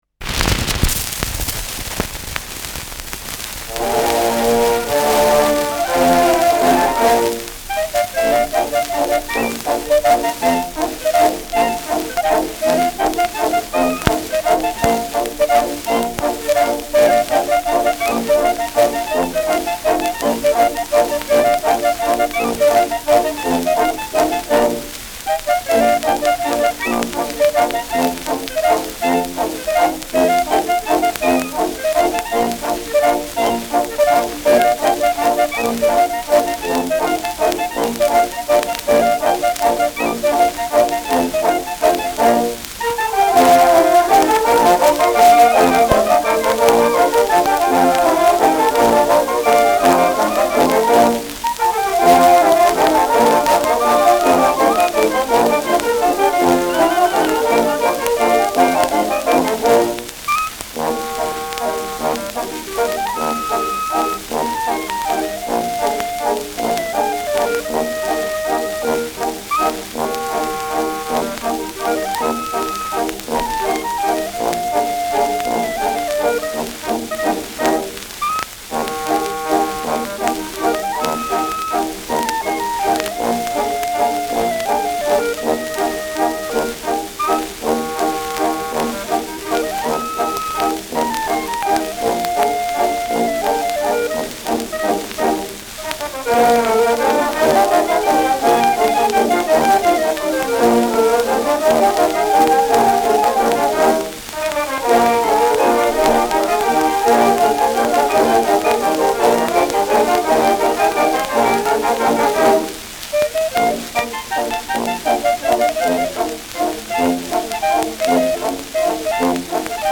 Schellackplatte
Abgespielt : Tonarm springt am Beginn kurz : Leichtes Leiern : Durchgehend leichtes Knacken
Kapelle Peuppus, München (Interpretation)
[München] (Aufnahmeort)